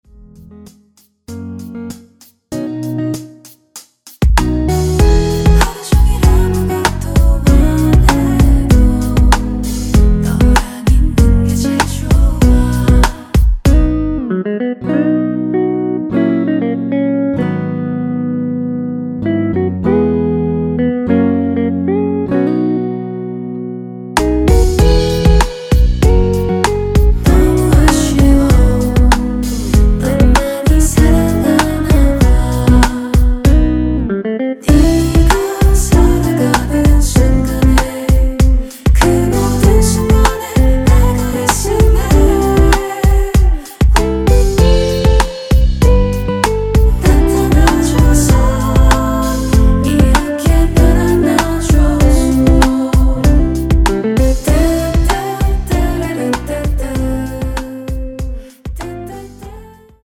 전주 없이 시작하는 곡이라 시작 카운트 넣어 놓았습니다.(일반 MR 미리듣기 확인)
원키에서(-1)내린 코러스 포함된 MR입니다.
앞부분30초, 뒷부분30초씩 편집해서 올려 드리고 있습니다.